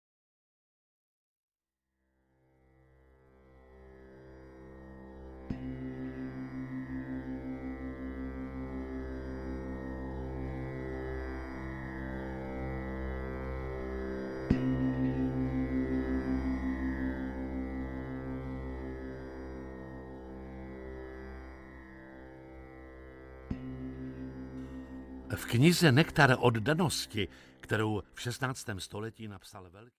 Ukázka z knihy
• InterpretJiří Lábus